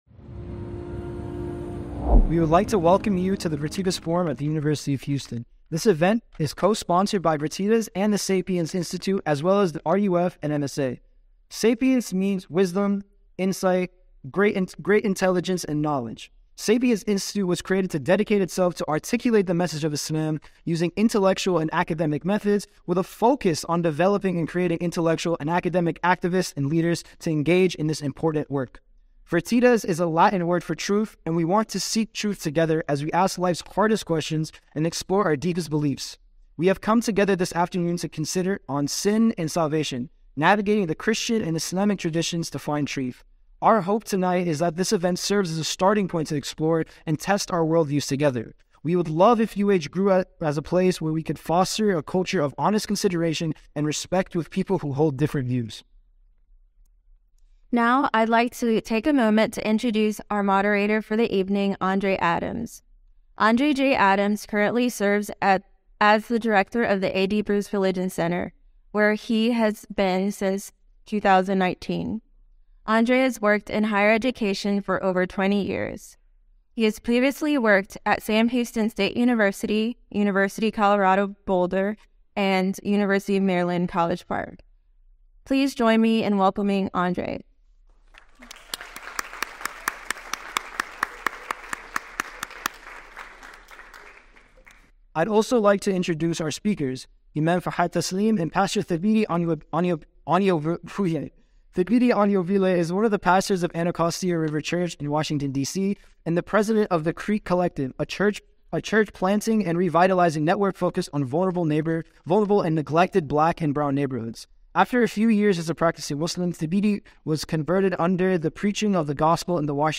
Dialogue ｜ On Sin & Salvation： Navigating the Christian and Islamic Traditions to Find Truth.mp3